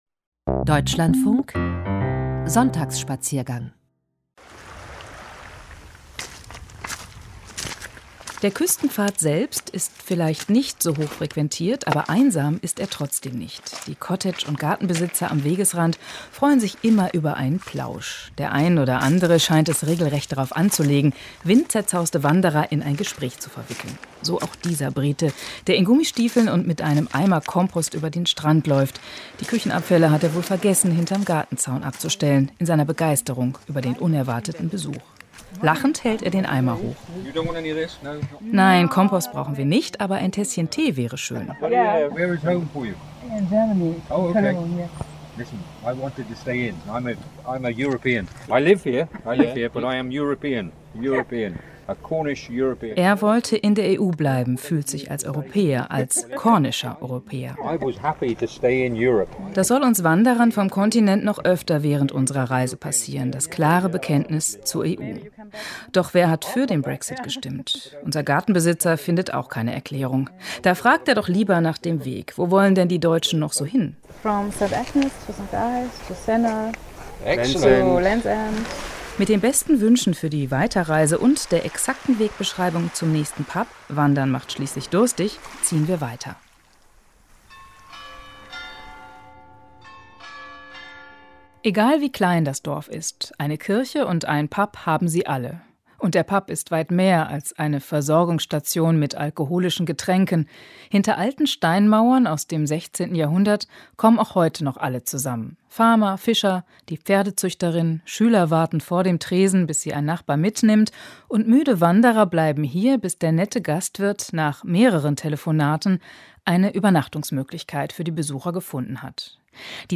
Singende Fischer in einem Pub im cornischen Küstenort Cadgwith
Einmal in der Woche kommen auch in dem kleinen Küstenort Cadgwith, ebenfalls als Filmkulisse für Rosamunde Pilcher-Romane bekannt, alle Bewohner der Gegend zusammen, um gemeinsam zu singen.